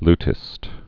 (ltĭst)